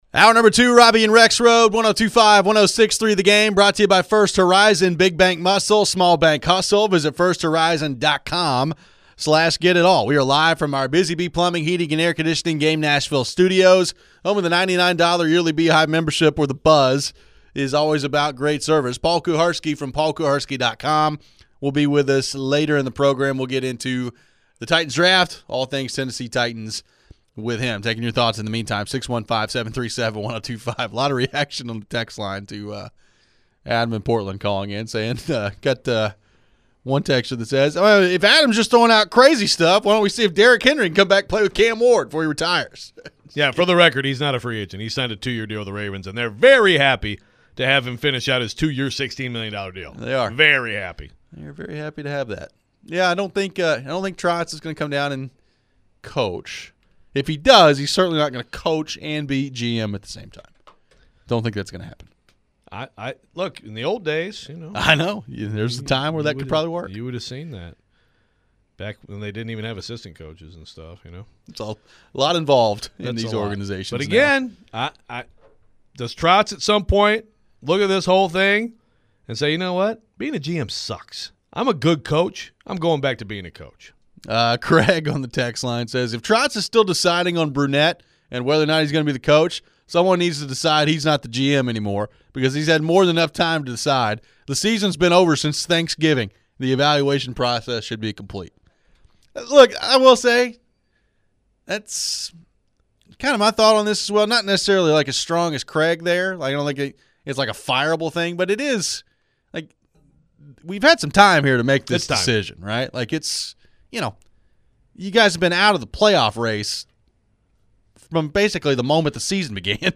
We get back into the phones on the NHL coaching changes and what is next with the Predators. What do we make of this Bill Belichick story and the influence his girlfriend is having on the UNC program? Who is doing more harm, Belichick or Deion?